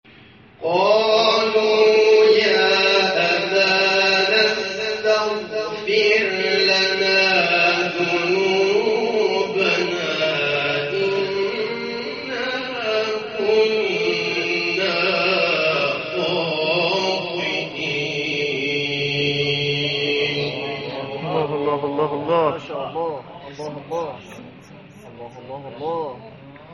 نغمات صوتی از قاریان ممتاز کشور